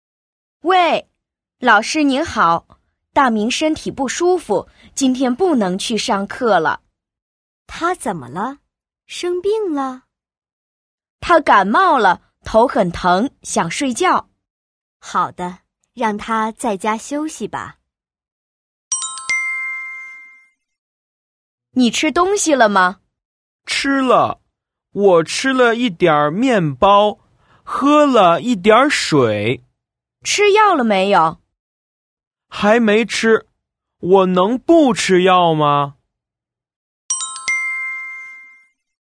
Đoạn hội thoại 1:
Đoạn hội thoại 2: